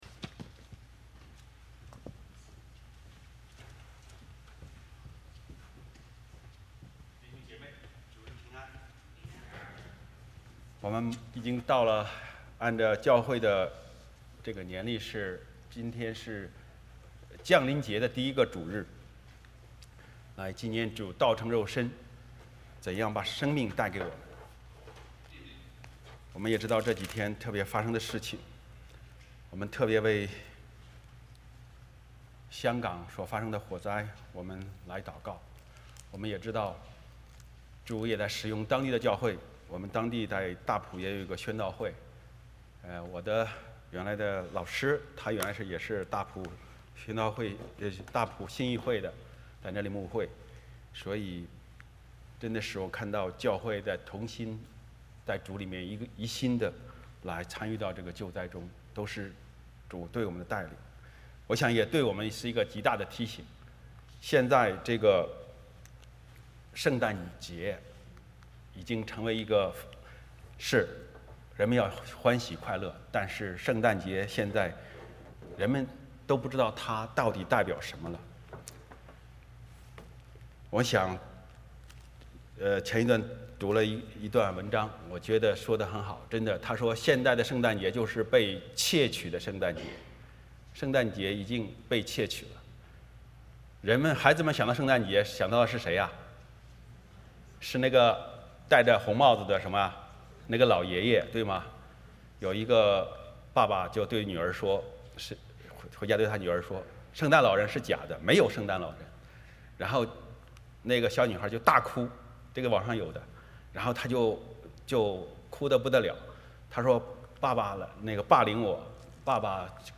路加福音 1:45-55 Service Type: 主日崇拜 欢迎大家加入我们的敬拜。